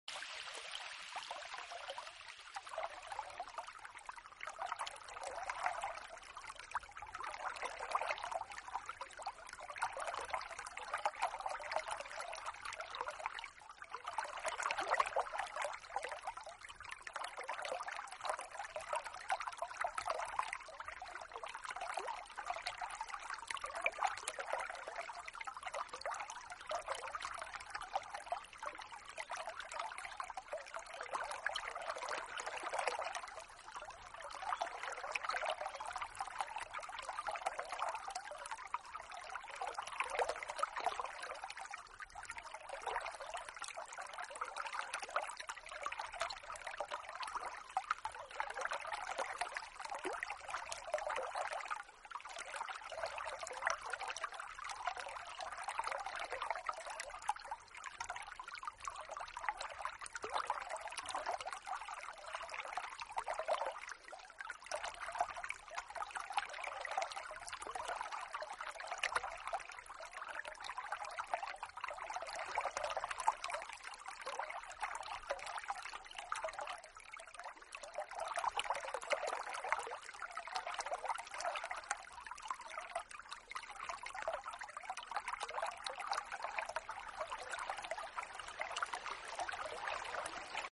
【新世纪纯音乐】